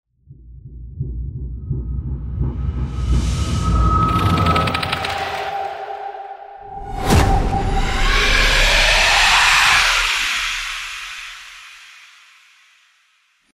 Sound Effect Horror Intro 5.mp3